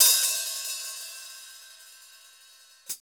Index of /90_sSampleCDs/Club-50 - Foundations Roland/CYM_xReal HiHats/CYM_x13 Hi Hat 1